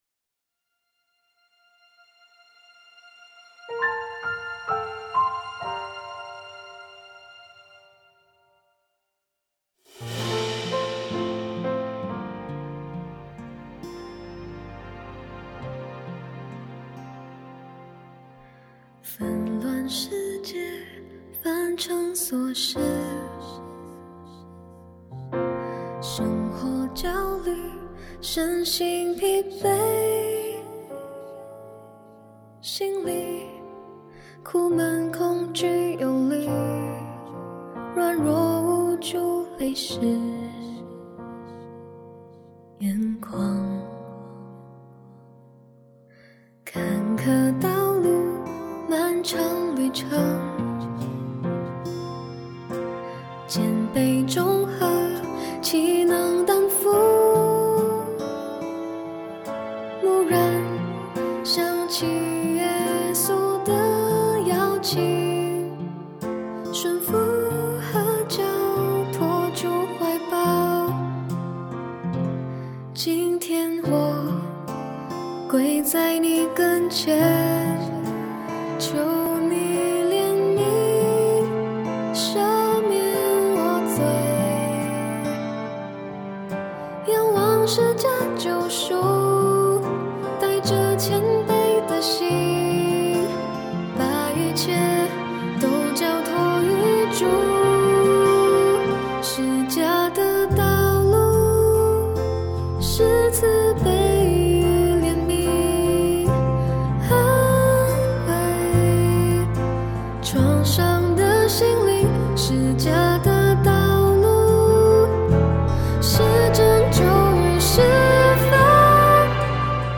主歌内加 了降五 G 小七和弦，為母亲不知所措的时候。